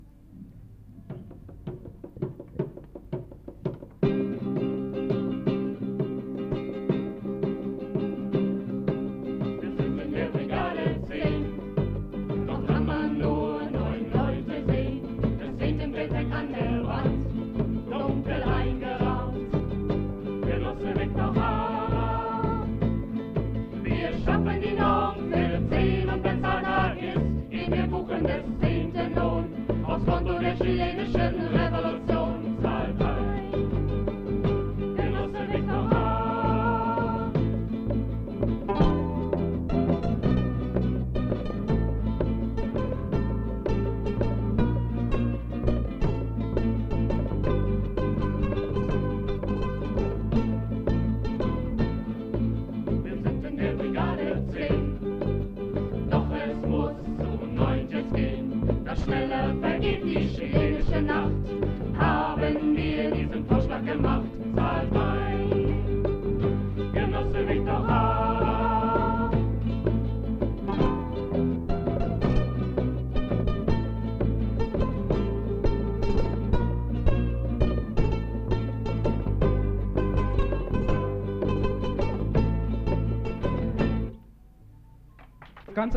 Viktor Jara1984 SUSI - SU Singe / Moskau / Rucksack-Programm 06Радуга/RadugaМЭИ1984Ingo Rahn: Songwriter